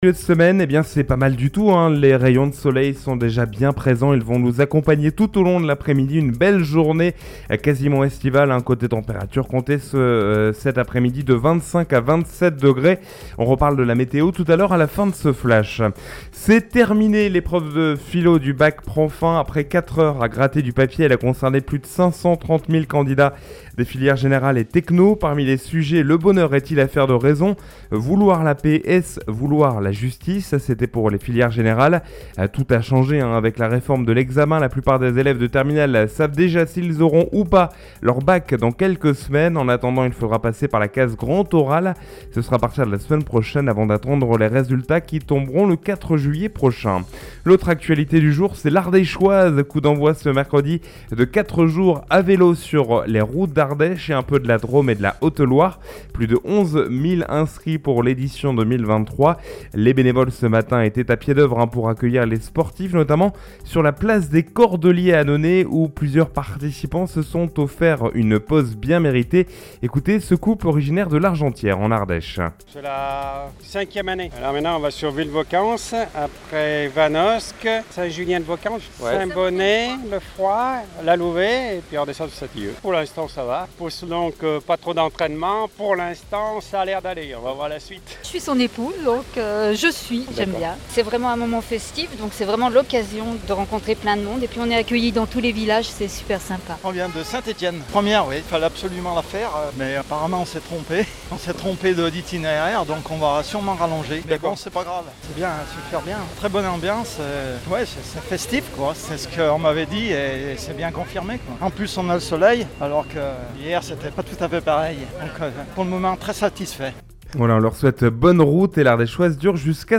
Mercredi 14 juin : Le journal de 12h